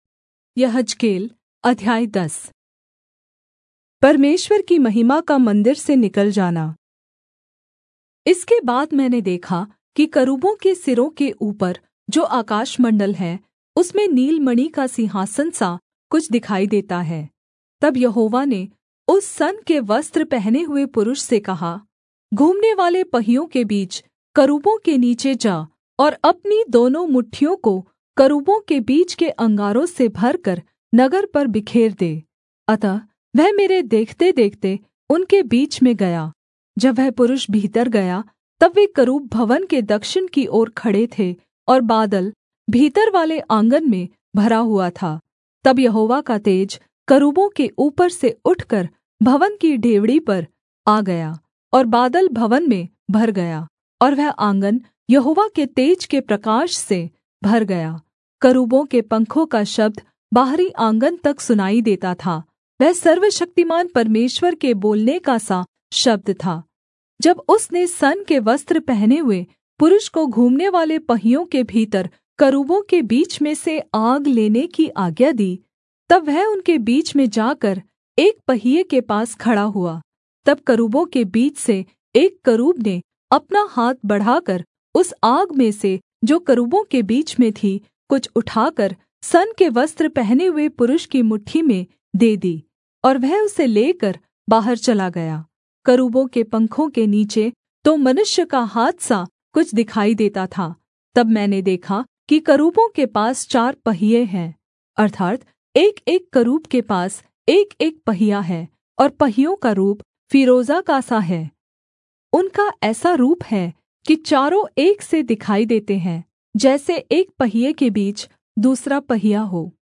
Hindi Audio Bible - Ezekiel 30 in Irvhi bible version